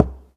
Knock8.wav